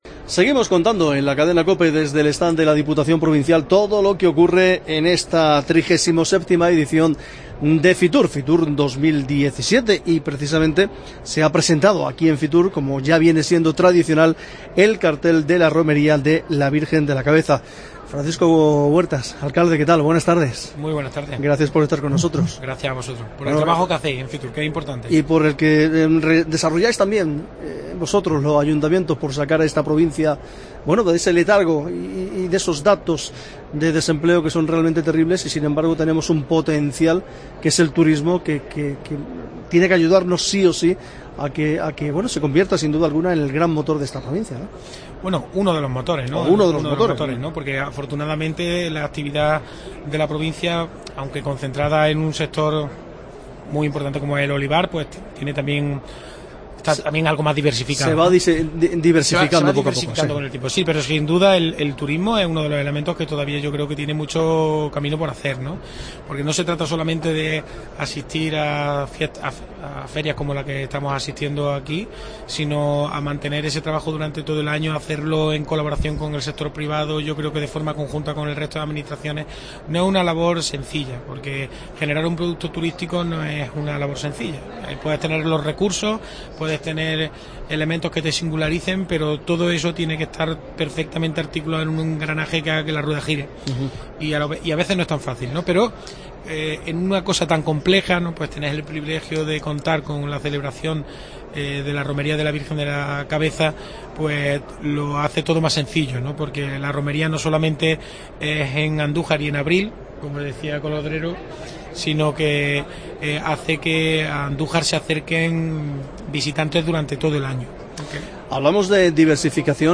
FITUR'17: Entrevista con Francisco Huertas, alcalde de Andújar